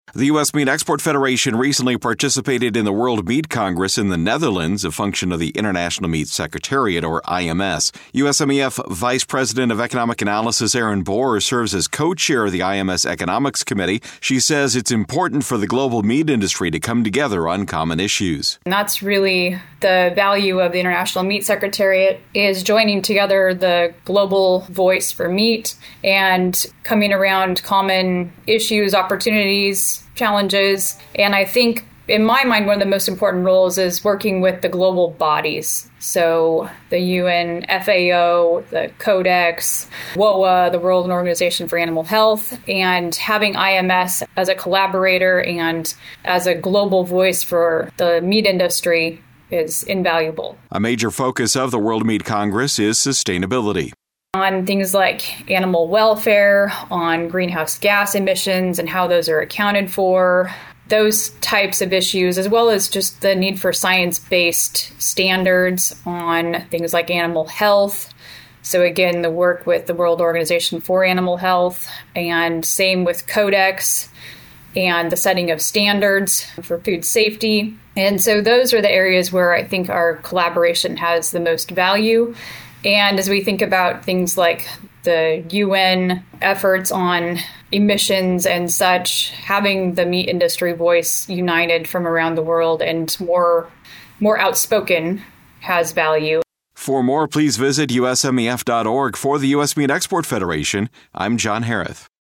In this audio report, she offers background on the World Meat Congress and talks about the importance of the global industry coming together to offer a unified voice on issues such as regulation and sustainability.